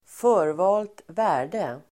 Uttal: [²f'ö:rva:lt ²vä:r_de]